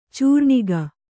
♪ cūrṇiga